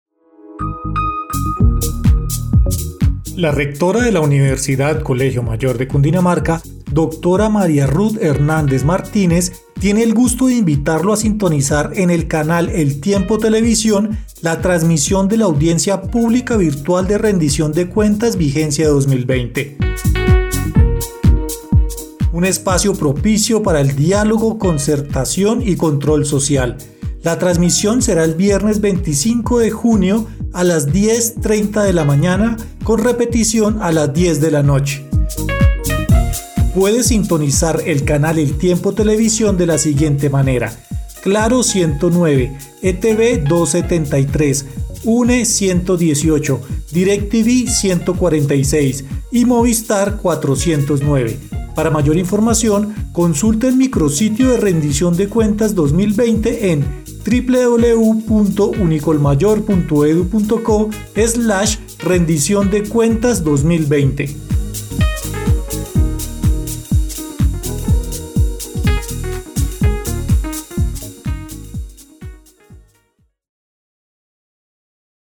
1. Promocional en la emisora institucional.
rendicion_cuentas_radial.mp3